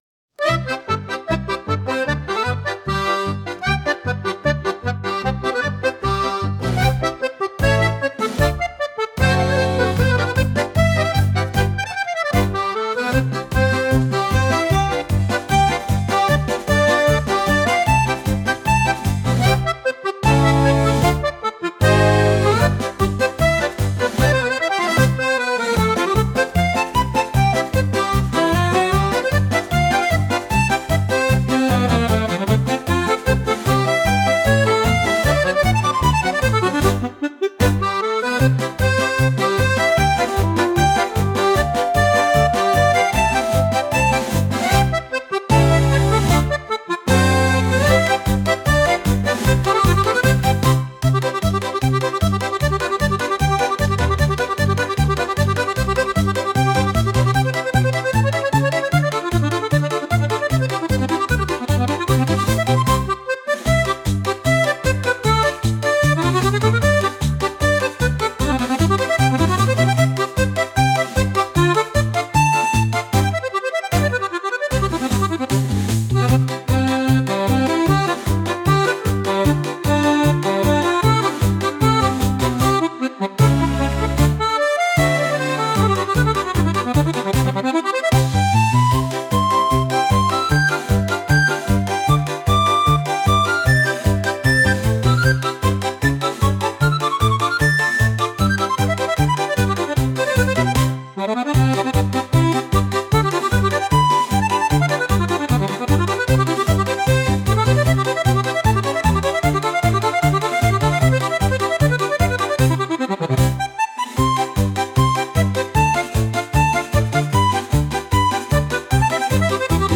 ちょっと速めで楽しくなるアコーディオン音楽です。